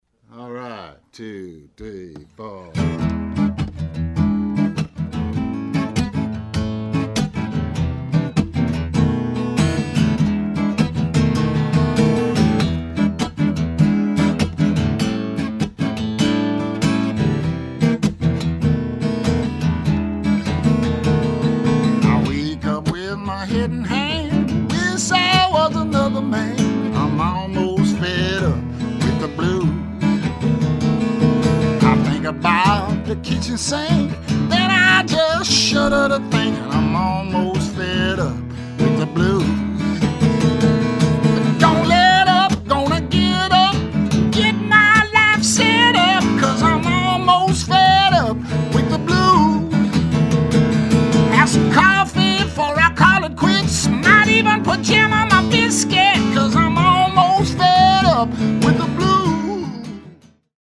(original two-track demos)